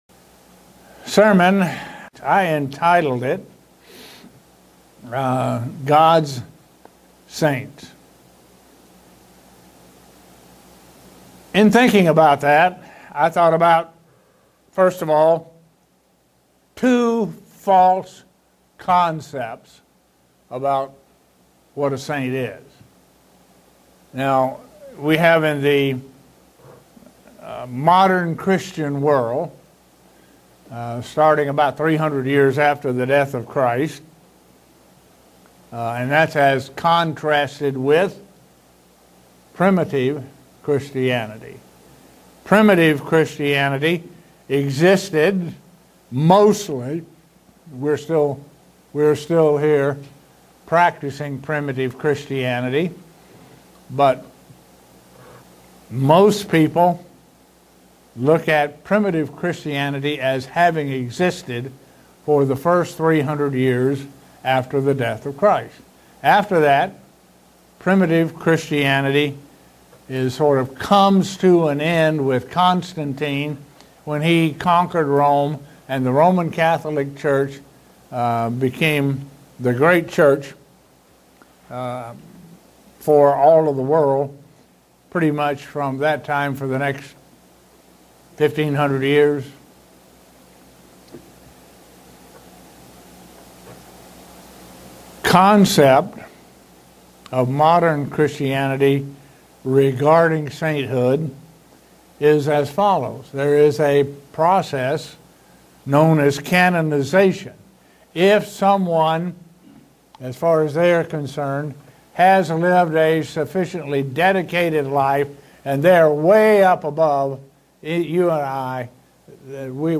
Given in Buffalo, NY
Print To show from the Bible how Gods' Saints are called and chosen. sermon Studying the bible?